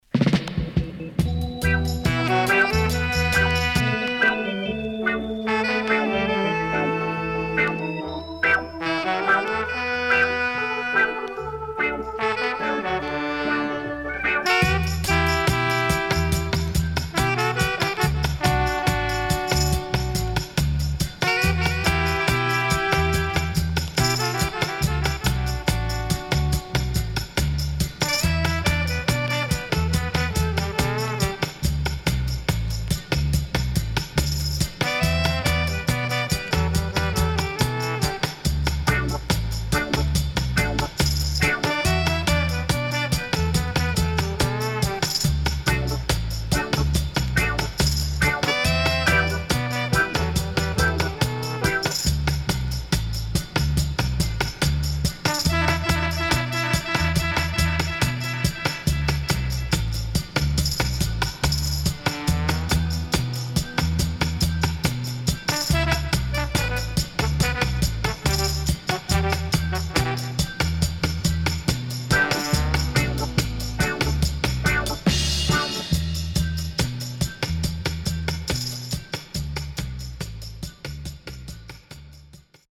SIDE A:少しプチノイズ入ります。